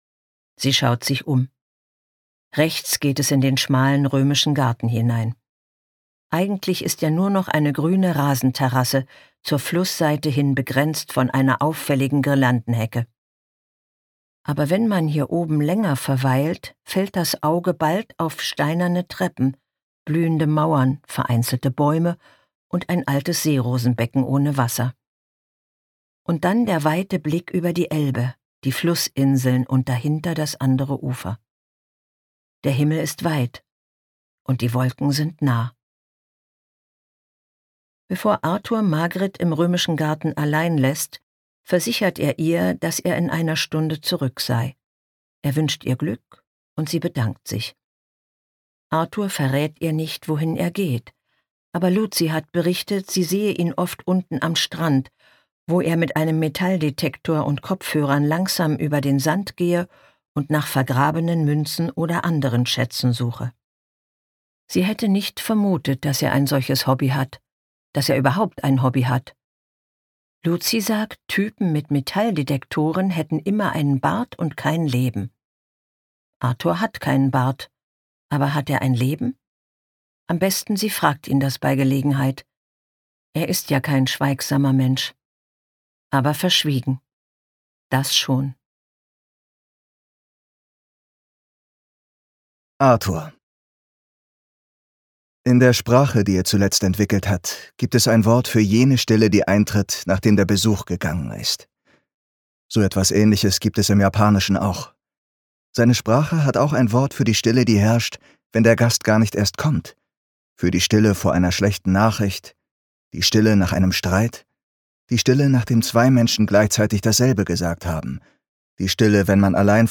2025 | 1. Auflage, Gekürzte Lesung